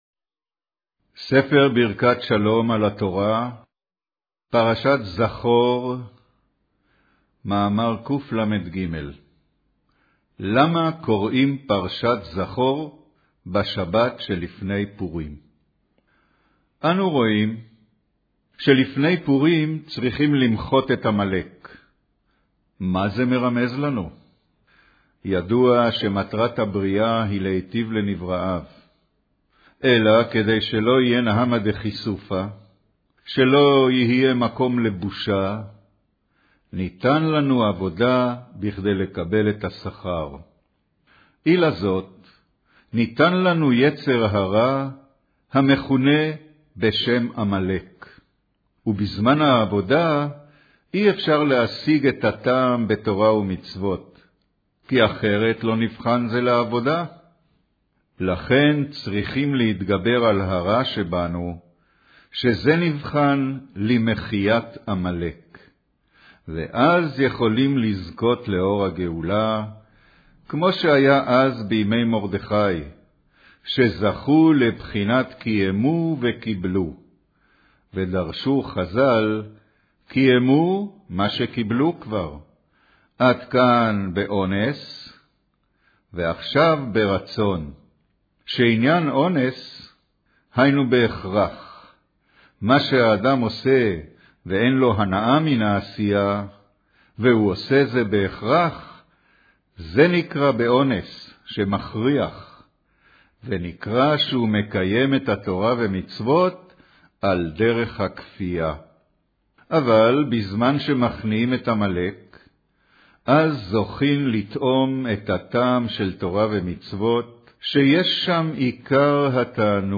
קריינות פרשת זכור, מאמר מחיית עמלק